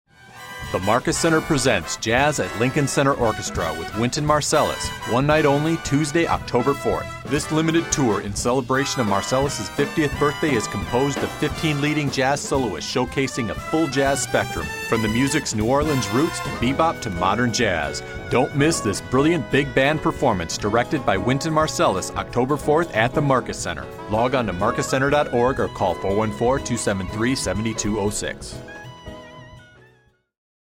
Wynton Marsalis Radio Commercial